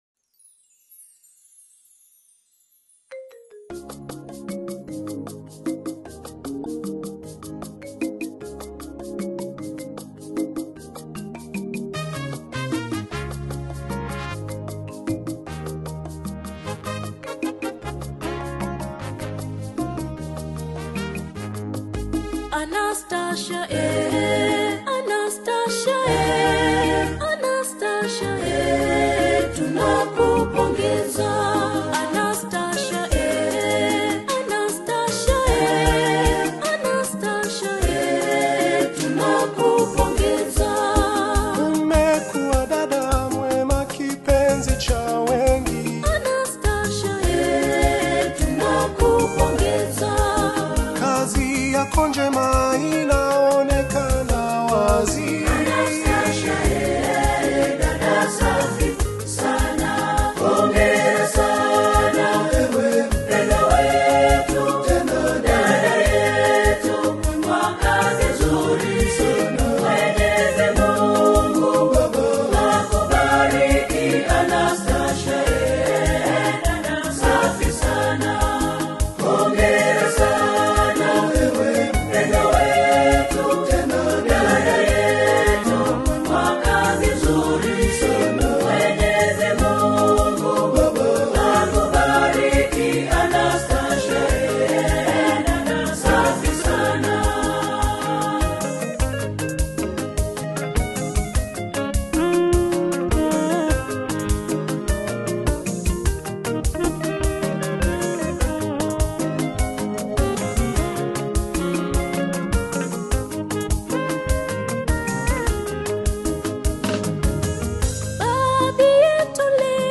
Tanzanian Catholic Gospel